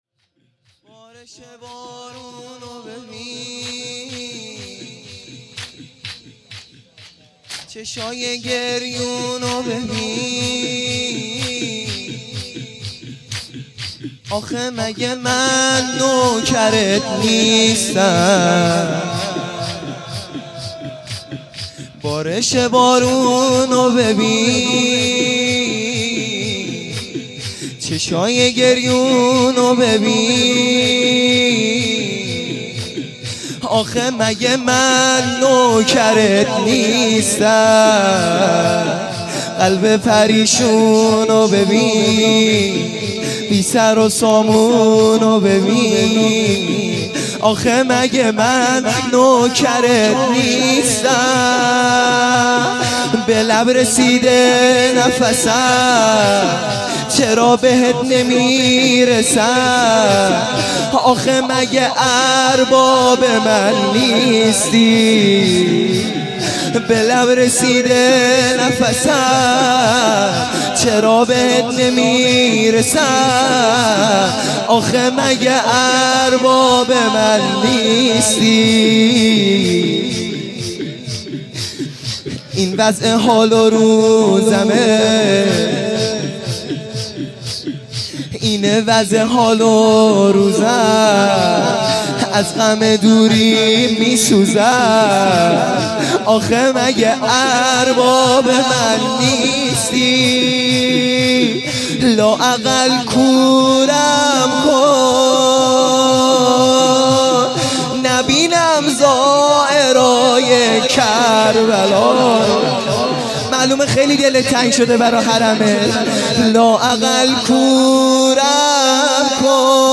شور | بارش بارون ببین